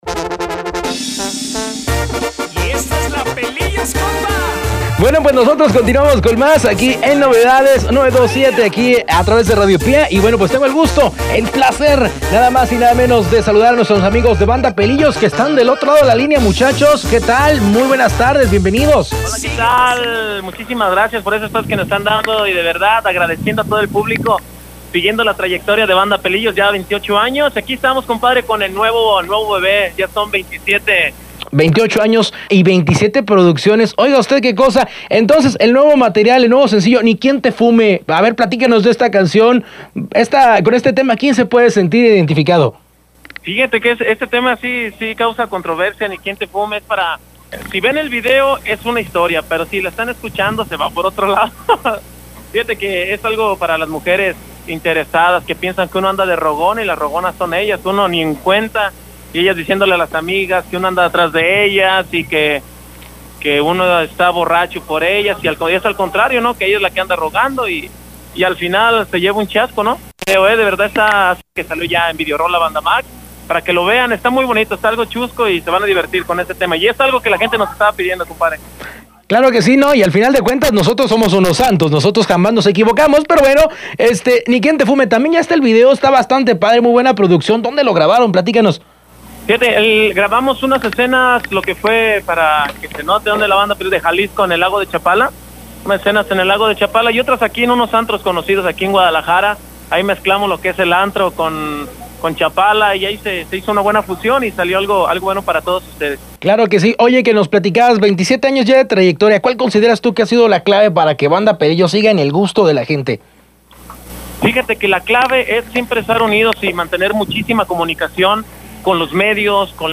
ENTREVISTA CON BANDA PELILLOS – RADIO PÍA
entrevista-pelillos.mp3